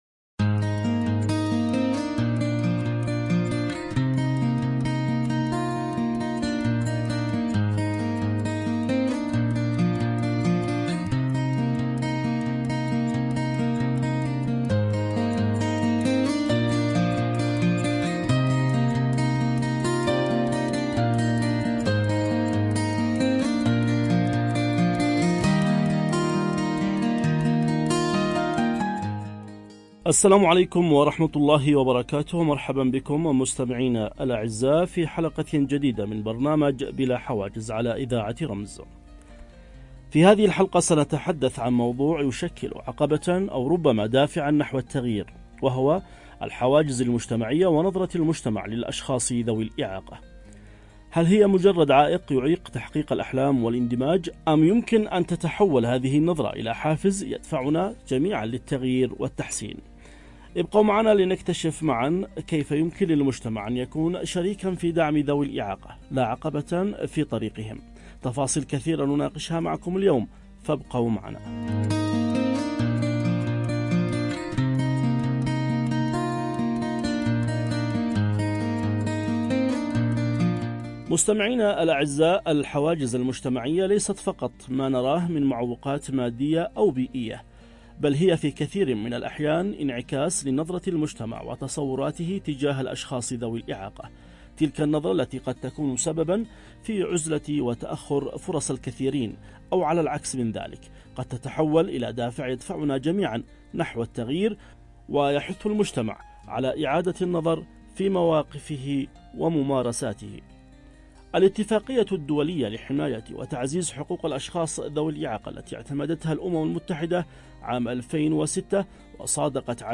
في نقاش عميق حول أثر النظرة المجتمعية على ذوي الإعاقة، وكيف يمكن تحويل التحديات إلى محفزات للنجاح.